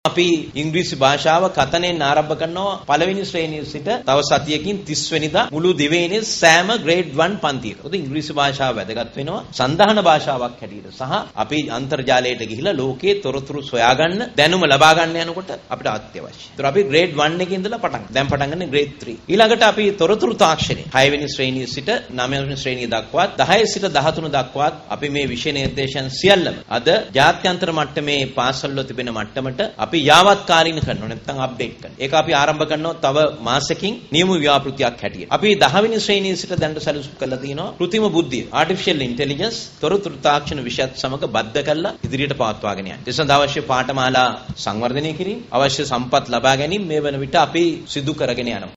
කොළඹ විශ්ව විද්‍යාලීය නව කලාගාරයේ පැවති වැඩසටහනකට එක්වෙමින් අමාත්‍ය සුසිල් ප්‍රේම්ජයන්ත මහතා මේ බව අවධාරණය කළා.